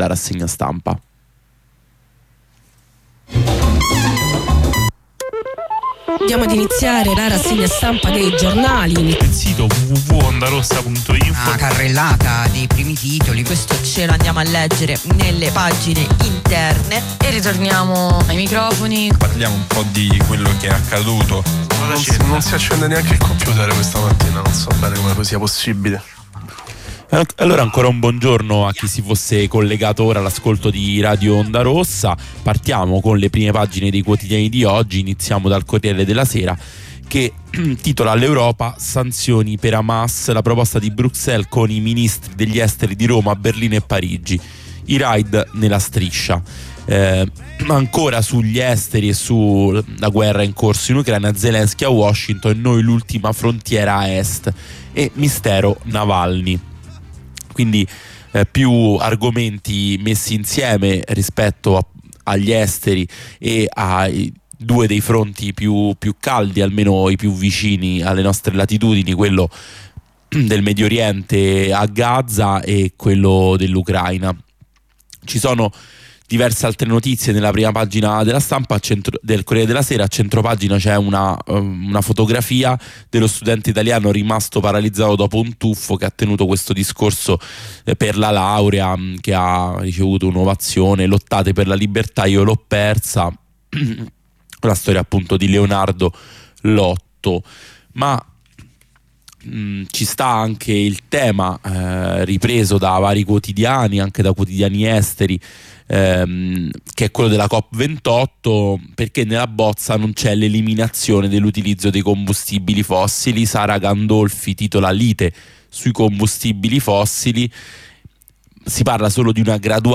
Lettura e commento dei quotidiani. Tutte le mattine su Radio Ondarossa.